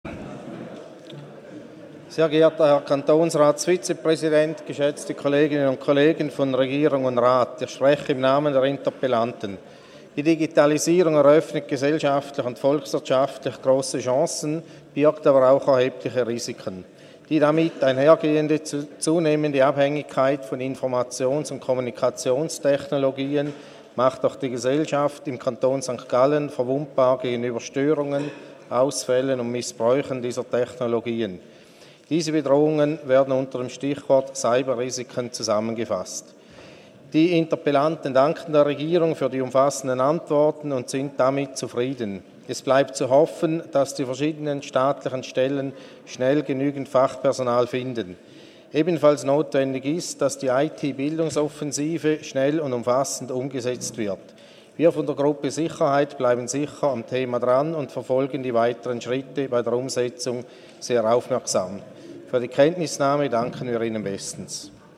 26.11.2019Wortmeldung
Session des Kantonsrates vom 25. bis 27. November 2019